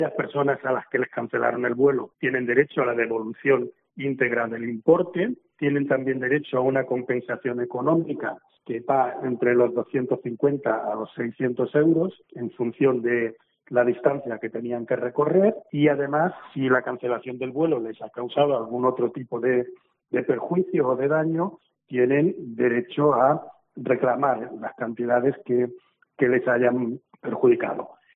CORTE DE VOZ CONSUBAL